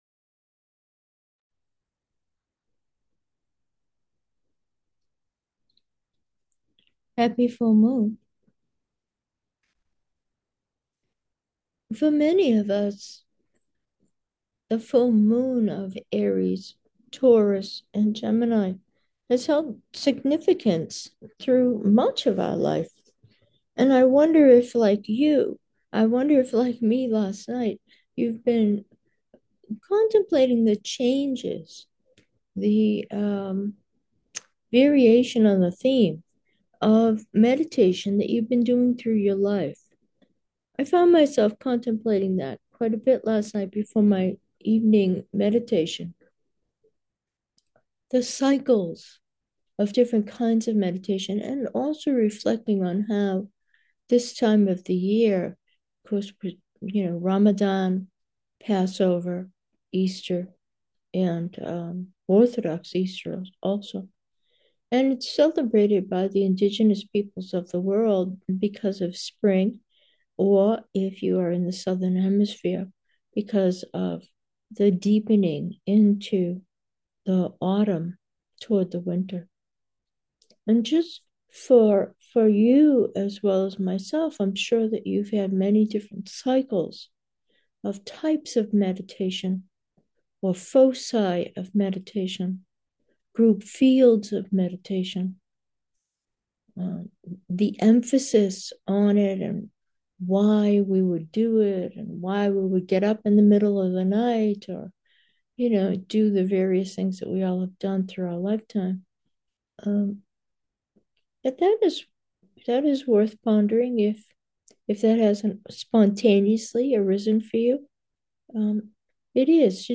Meditation: with the world, full moon